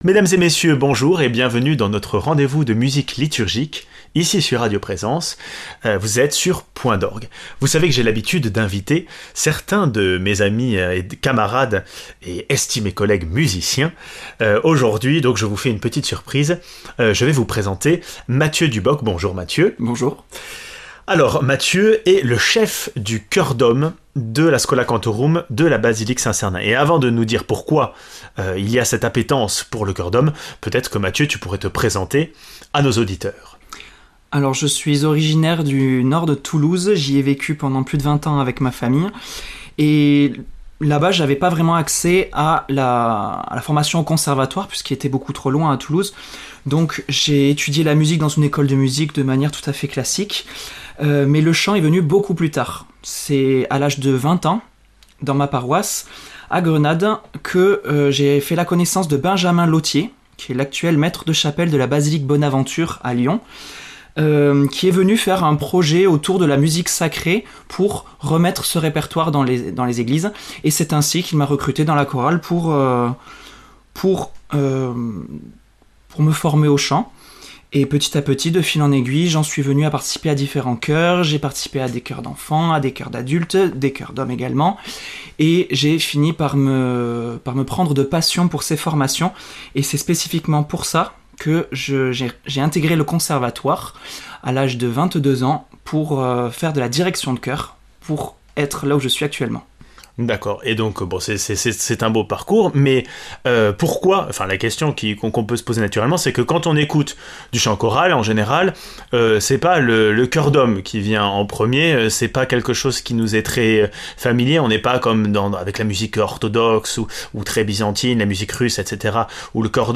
entrons dans ce monde aux sonorités profondes et riches.